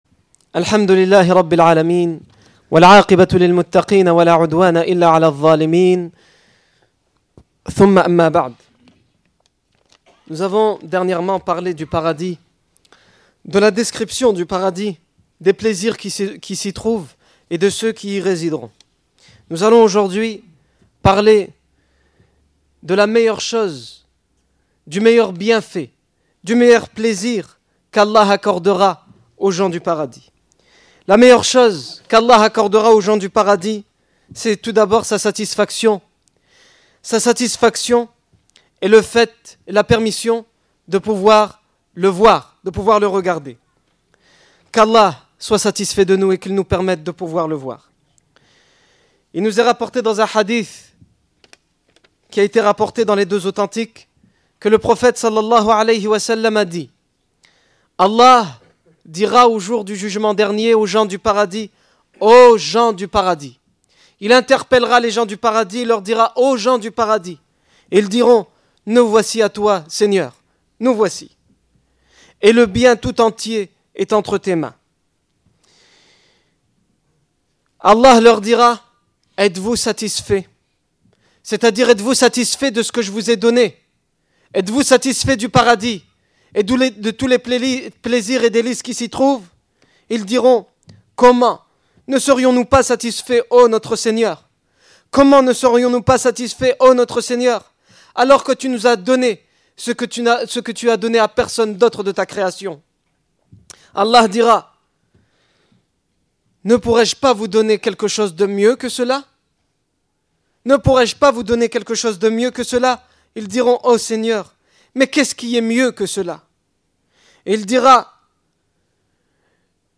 Discours du 23 avril 2010
Discours du vendredi